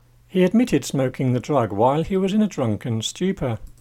DICTATION 3